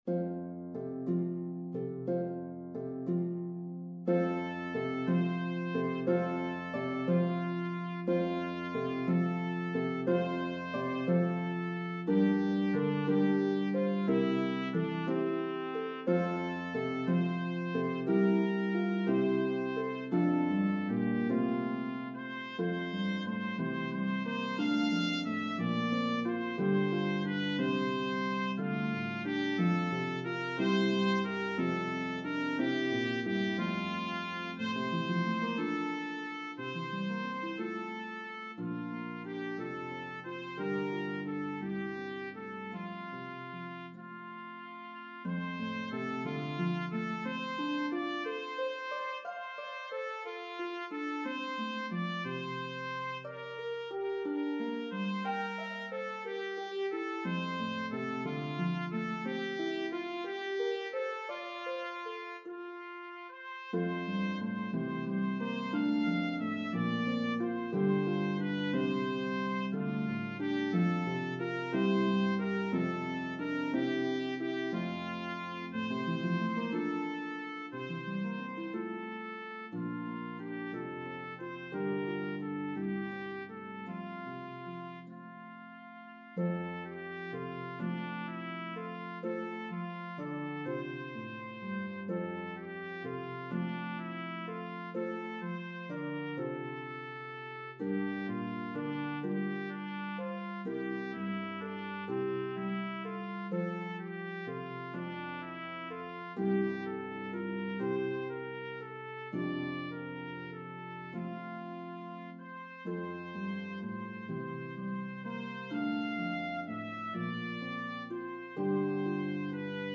traditional Christmas hymn
Harp and Trumpet in B-flat version